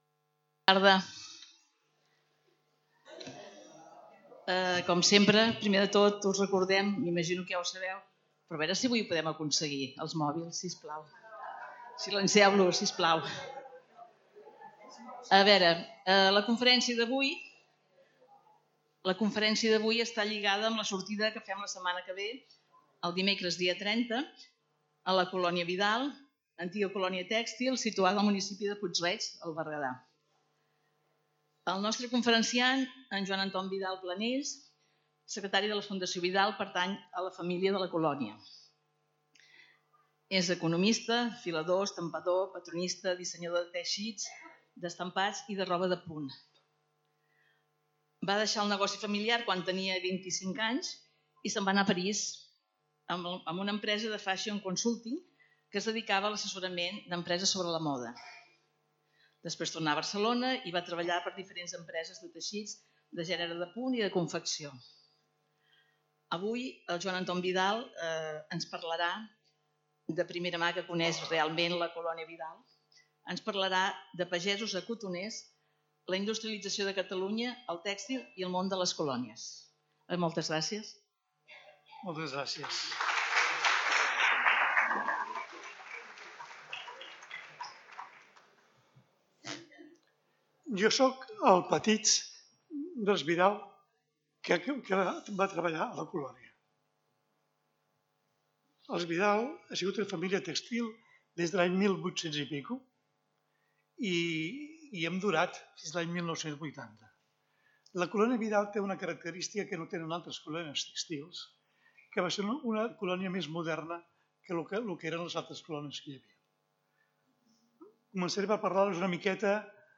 Lloc: Centre Cultural Calisay
Categoria: Conferències